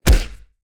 face_hit_small_78.wav